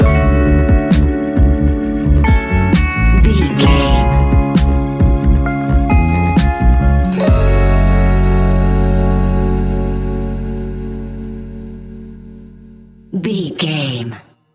Ionian/Major
F♯
laid back
Lounge
sparse
chilled electronica
ambient
atmospheric